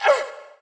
damage_1.wav